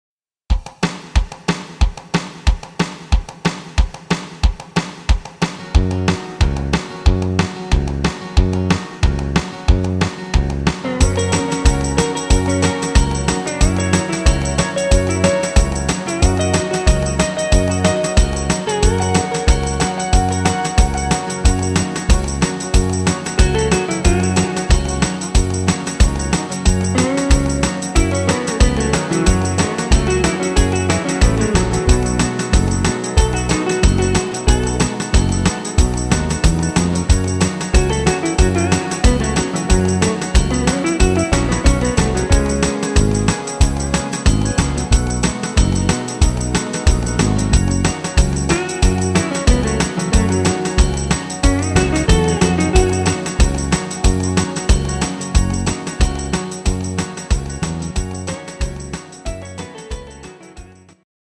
Rhythmus  Rock'n Roll
Art  Englisch, Oldies